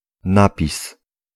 Ääntäminen
US : IPA : [ɪn.ˈskrɪp.ʃən]